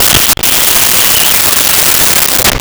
Kiss With Pop 01
Kiss With Pop 01.wav